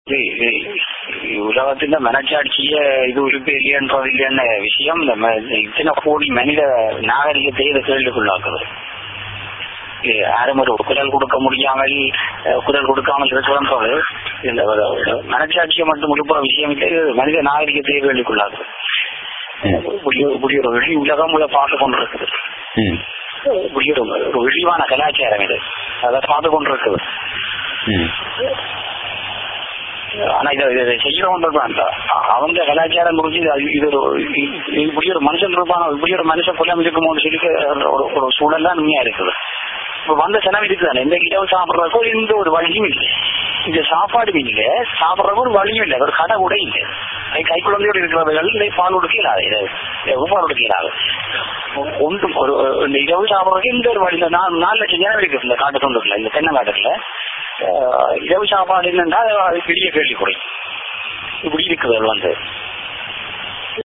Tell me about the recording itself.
TamilNet publishes direct eyewitness accounts from the street of Udaiyaarkaddu.